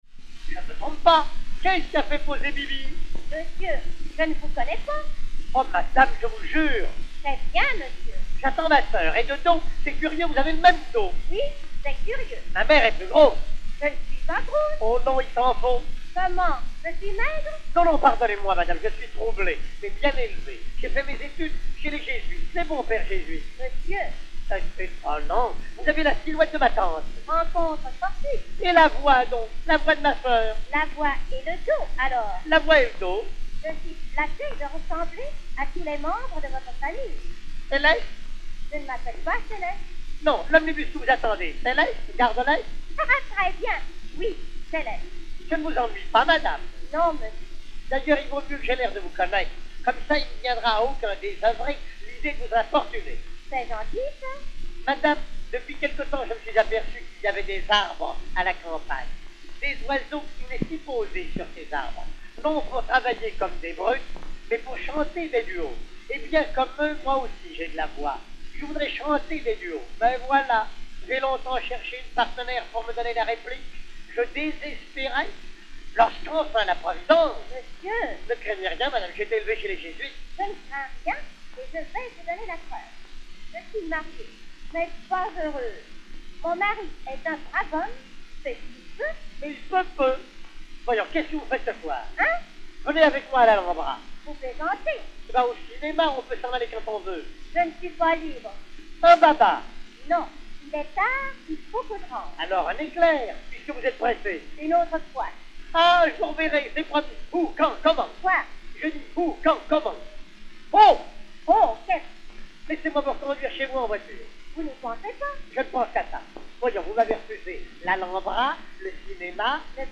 saynète à deux personnages (Félix Galipaux)
Félix Galipaux du Palais-Royal